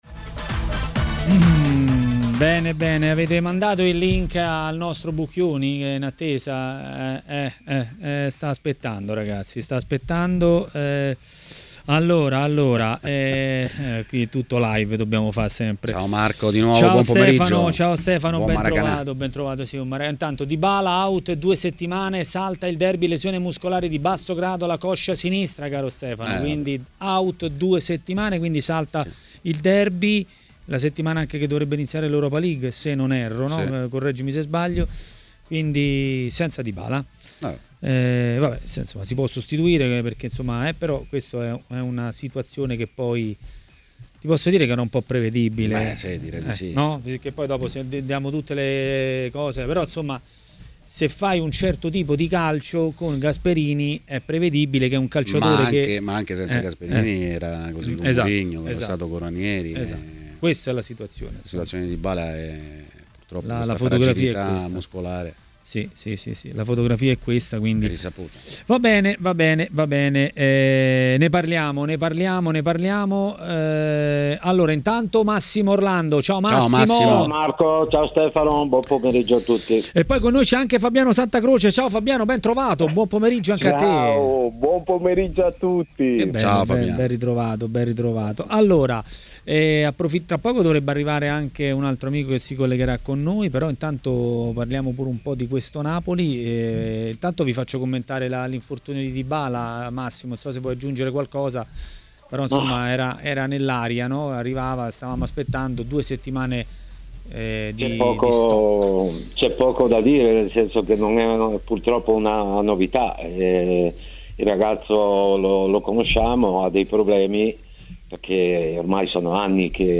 L'ex calciatore Fabiano Santacroce è intervenuto a TMW Radio, durante Maracanà.